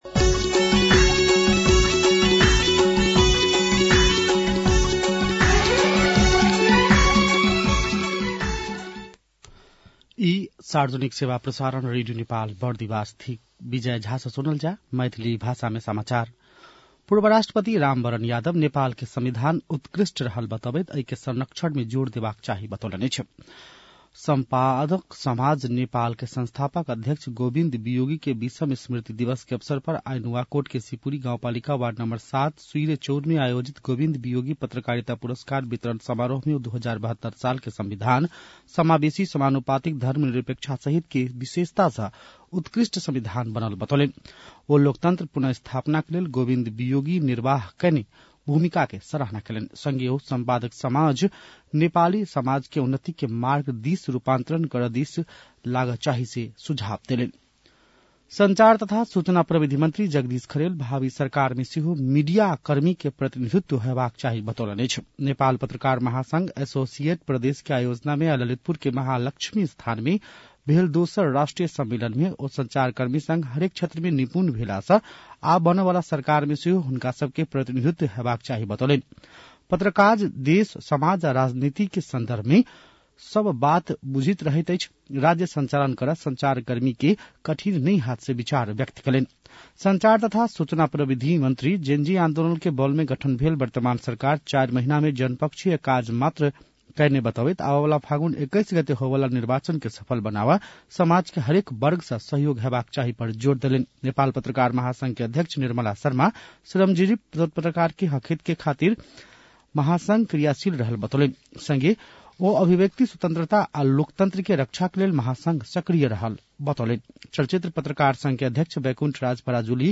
मैथिली भाषामा समाचार : ३ माघ , २०८२
6.-pm-maithali-news-1-5.mp3